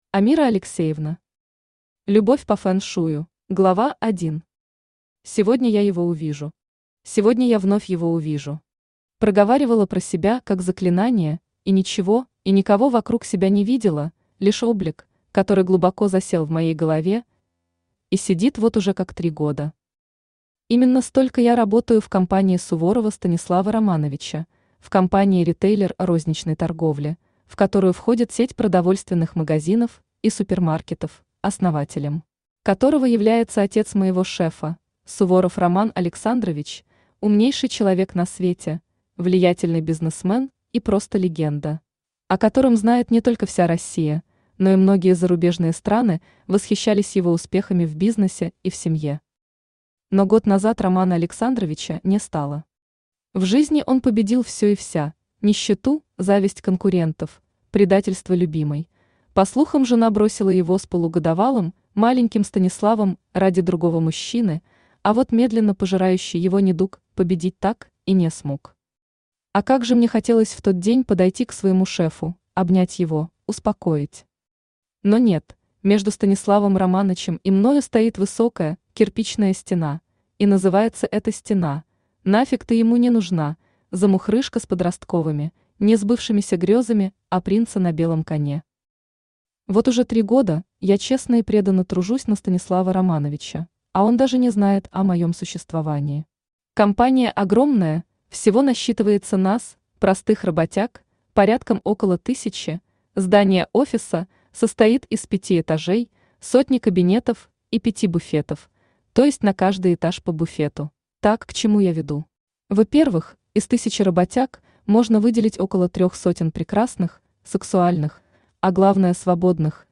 Aудиокнига Любовь по фэн-шую Автор Амира Алексеевна Читает аудиокнигу Авточтец ЛитРес.